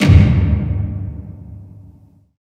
specialhit.wav